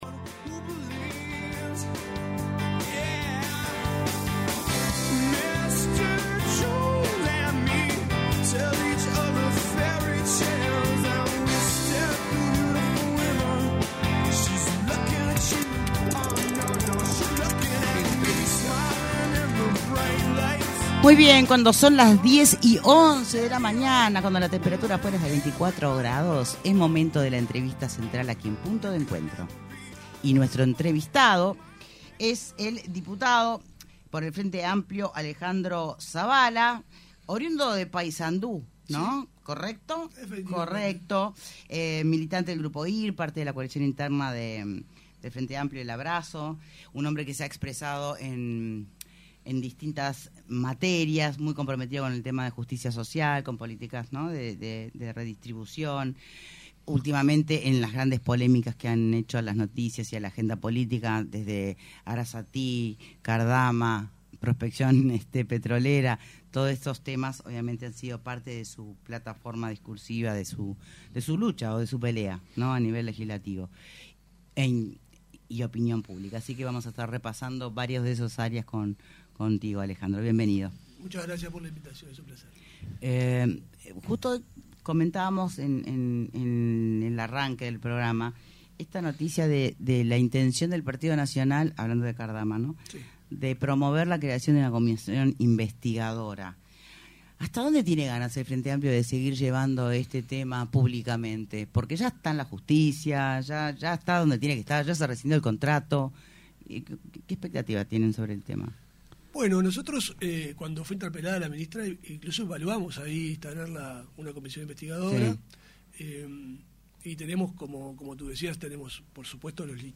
ENTREVISTA: ALEJANDRO ZAVALA La oferta de Cardama es “turbia” En entrevista con Punto de Encuentro, el diputado del Frente Amplio Alejandro Zavala dijo que la oferta que había presentado el astillero español Cardama es “turbia” y que de haber hecho un procedimiento competitivo regulado por el Tocaf, habría que haberla descartado por “temeraria”.